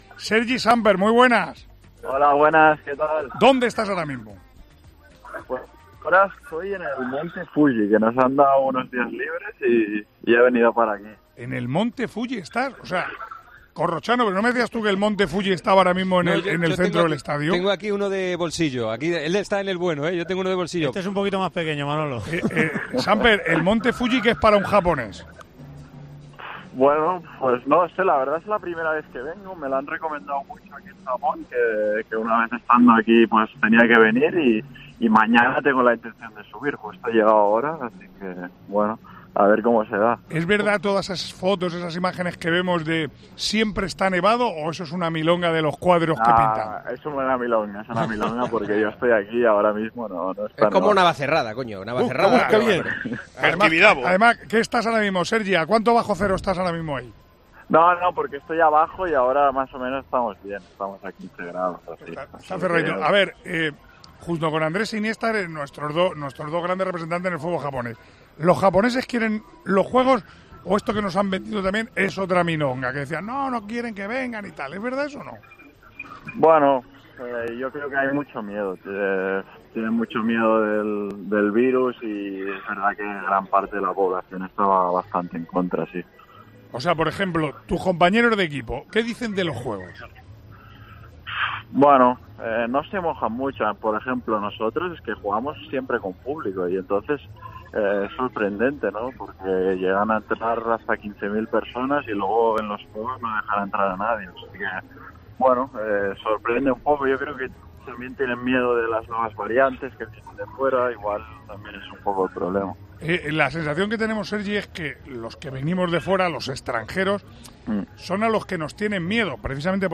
AUDIO: El jugador español y futbolista del Vissel Kobe, atendió a COPE desde el Monte Fuji de Tokio.